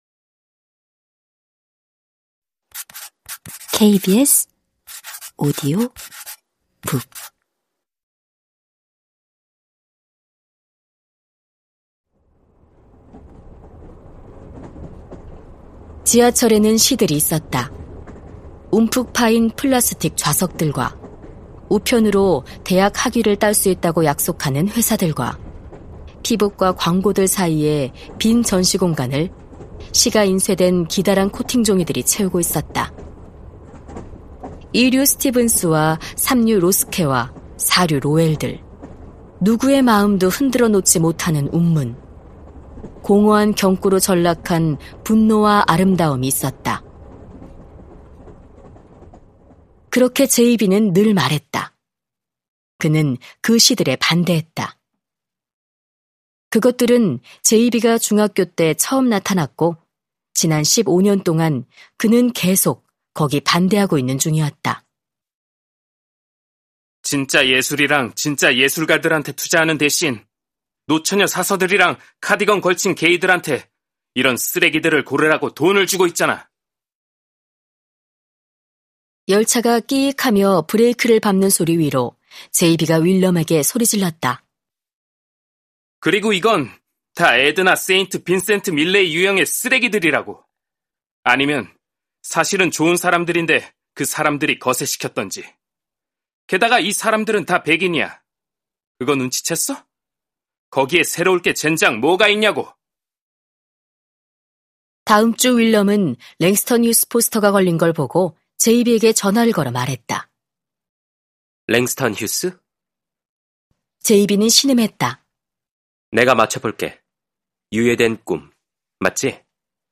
KBS오디오북_리틀 라이프_한야 야나기하라_성우